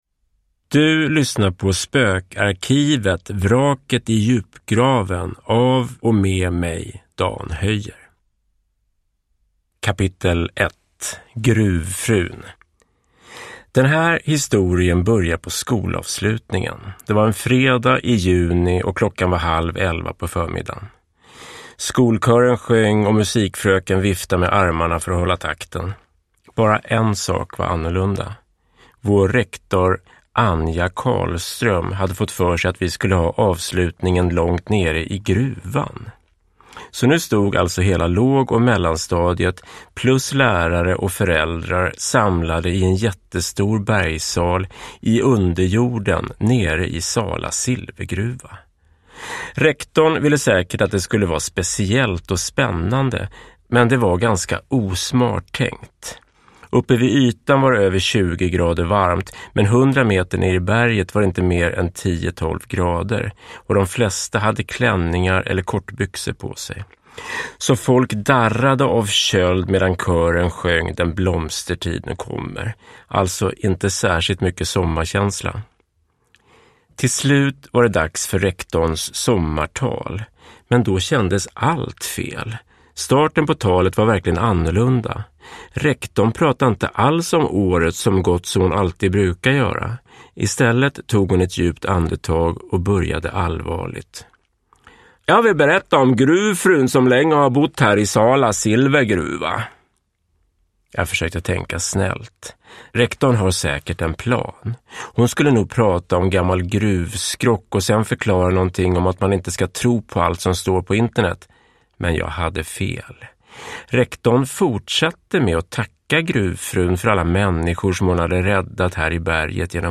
Vraket i djupgraven – Ljudbok